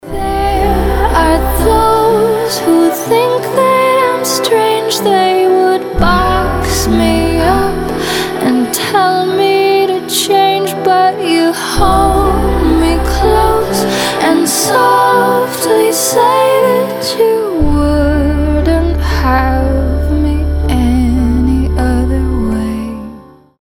• Качество: 320, Stereo
мелодичные
спокойные
красивый женский голос
indie pop
нежные
трогательные
Очаровательный голос!